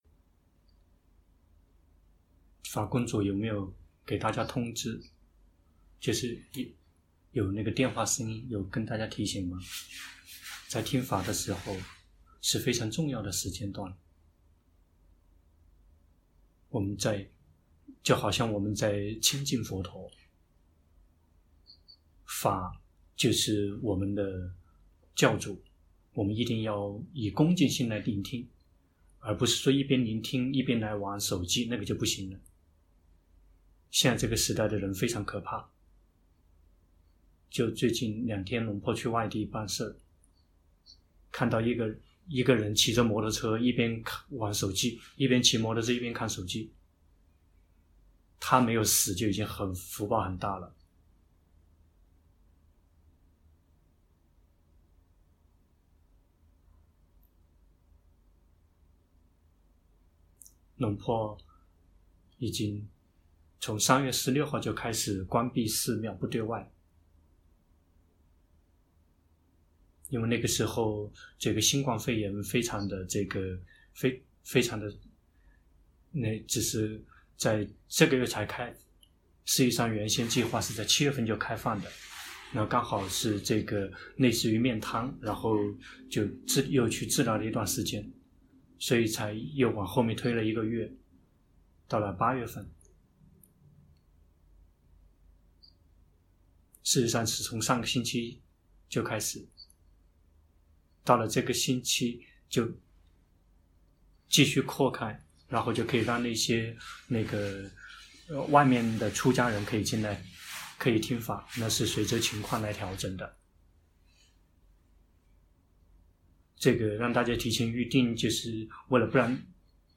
泰國解脫園寺 同聲翻譯